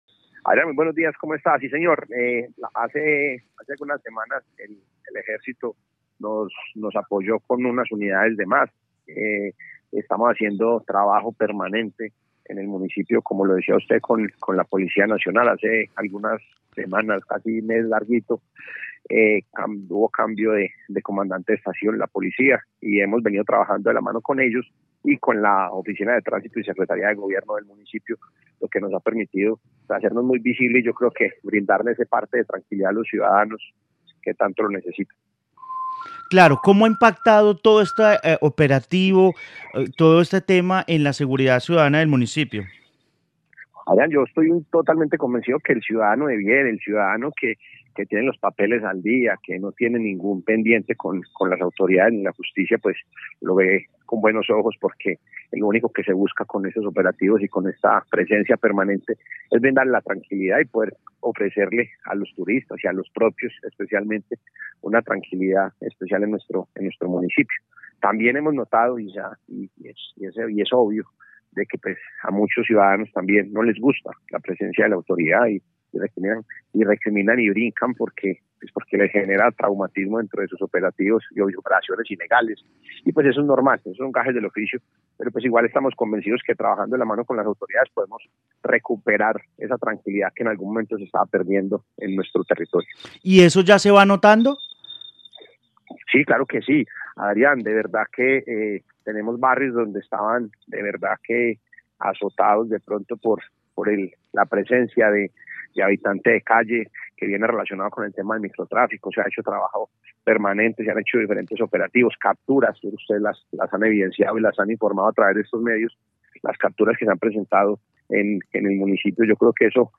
Juan Manuel Rodríguez, alcalde de Quimbaya, Quindío
En Caracol Radio Armenia hablamos con el mandatario del municipio luz del Quindío sobre las amenazas que recibieron a través de redes sociales.